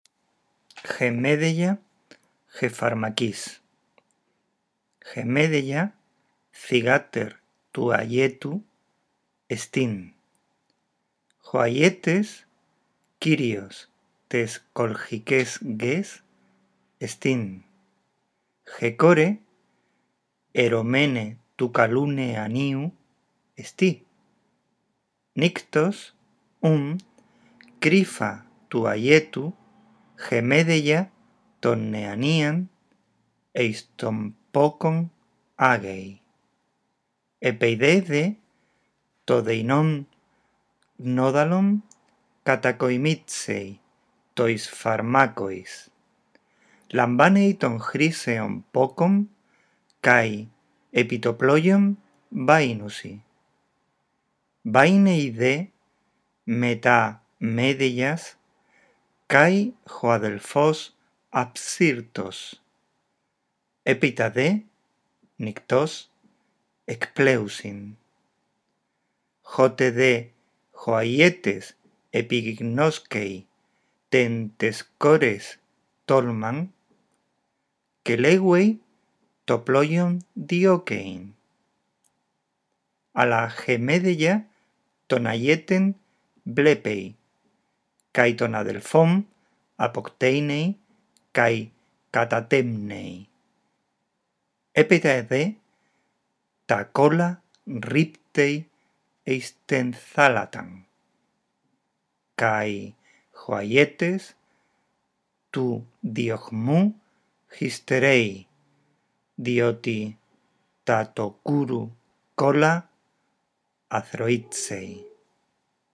El texto narra cómo Medea, para ayudar a Jasón a conquistar el vellocino de oro, es capaz de traicionar a su propia familia. Lee despacio y en voz alta el texto griego; procura pronunciar unidos los sintagmas, haz pausas en los signos de puntuación.